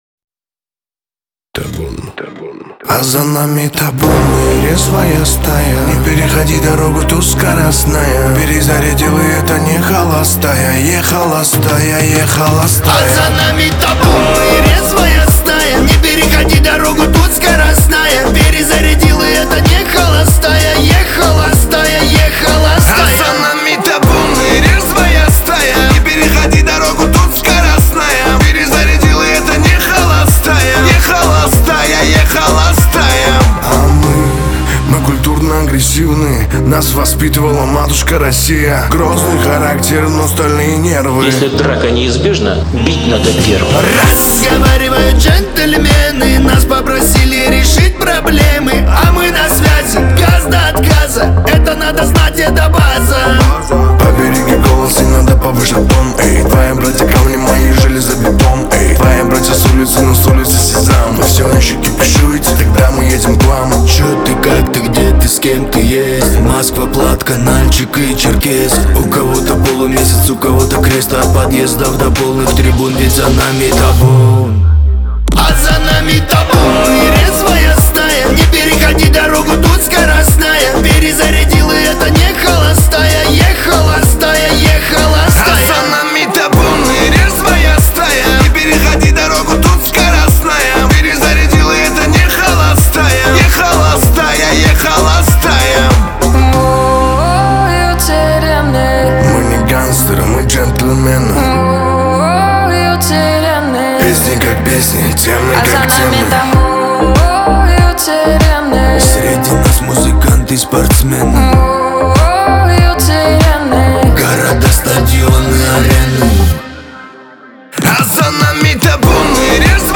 Лирика , дуэт , Кавказ – поп